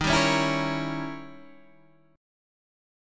D# 7th Flat 9th